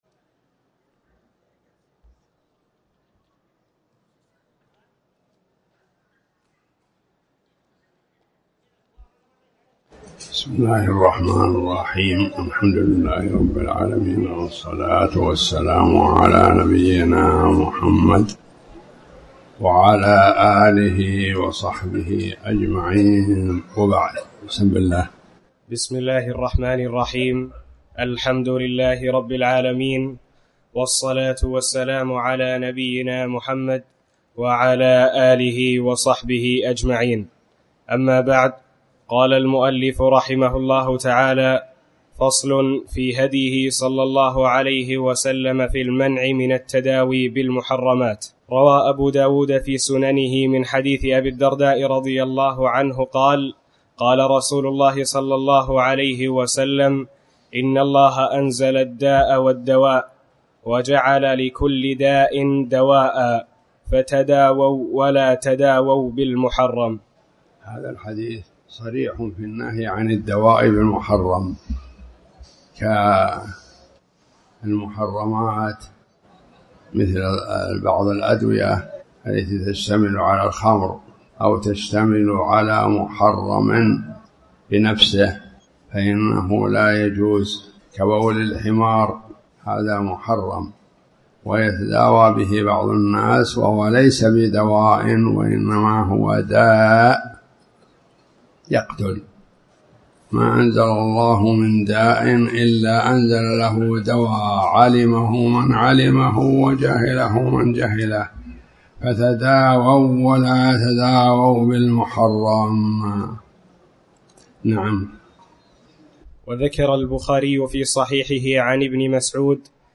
تاريخ النشر ١٥ محرم ١٤٣٩ هـ المكان: المسجد الحرام الشيخ